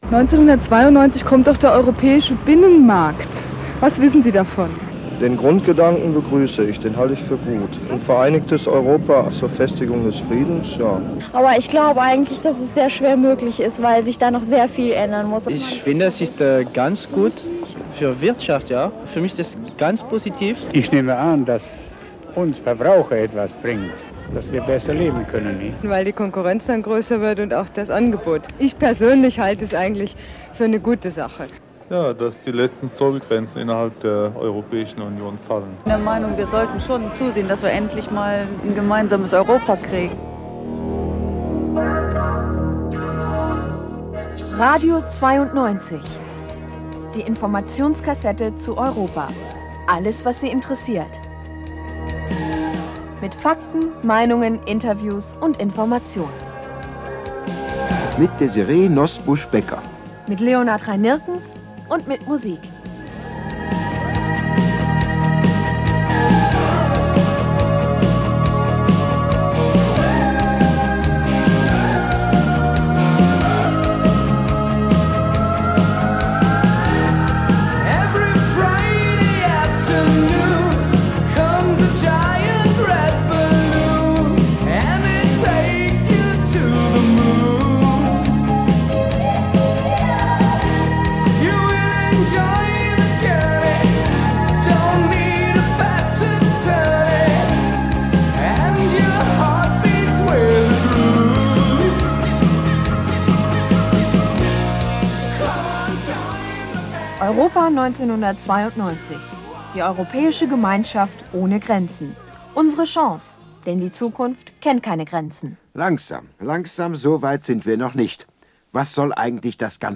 Bonn war noch Regierungssitz, und wenn ein Ministerium den jugendlichen Zielgruppen etwas zu erzählen hatte, was nicht auf einen Flyer passte, verteilte es Compact-Cassetten wie diese, die z.B. von Désirée Nosbusch moderiert wurden, damals allen noch gut erinnerlich als polyglottes Wunderkind von Radio Luxemburg.